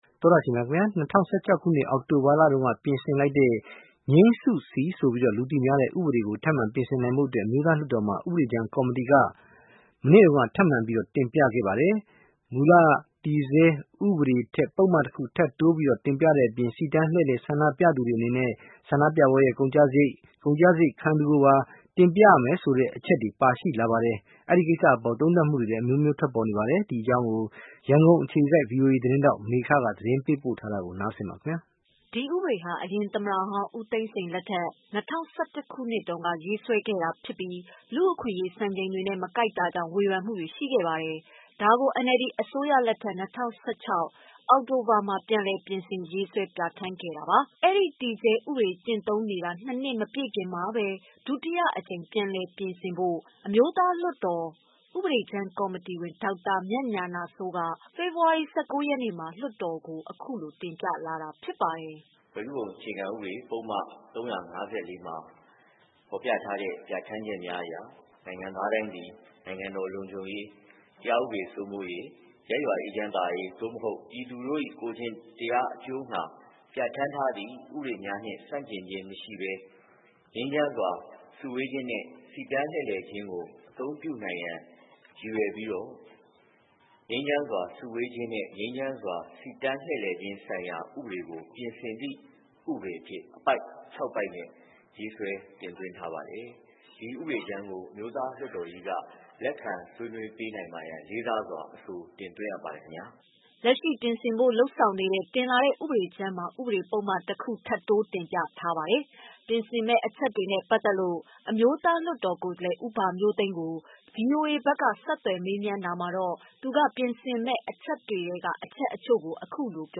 လက်ရှိ ပြင်ဆင်ဖို့ လုပ်ဆောင်နေတဲ့ တင်လာတဲ့ ဥပဒေကြမ်းမှာ ဥပဒေပုဒ်မ တခုထပ်တိုးတင်ပြထားပါတယ်။ ပြင်ဆင်မယ့် အချက်တွေနဲ့ ပတ်သတ်လို့ အမျိုးသားလွှတ်တော် ကိုယ်စားလှယ် ဦးဘမျိုးသိန်းကို ဗွီအိုအေက ဆက်သွယ်မေးမြန်းတာမှာတော့ သူက ပြင်ဆင်မယ့် အချက်တွေထဲက အချက်အချို့ကို အခုလို ပြောပြပါတယ်။